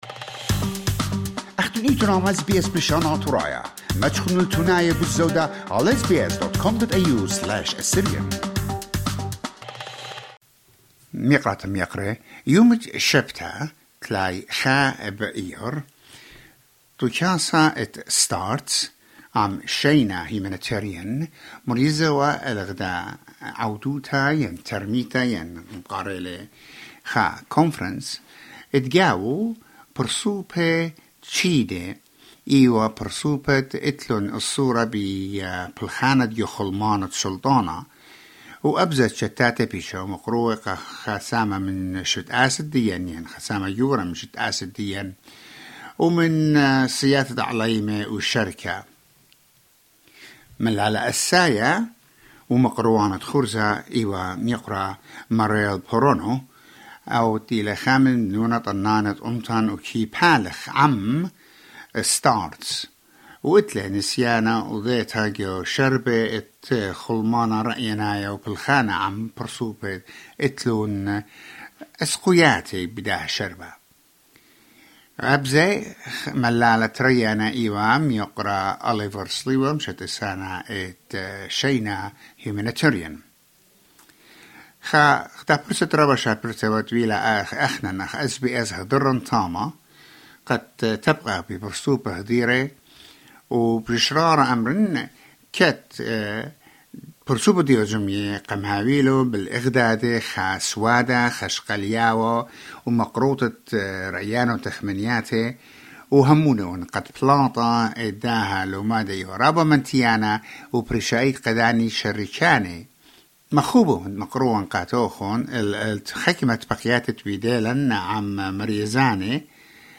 Several participants shared their thoughts on the conference.